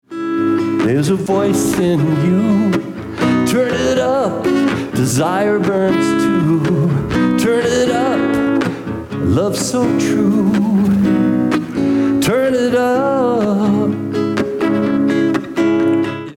Original Song from Fundraiser Concert 2016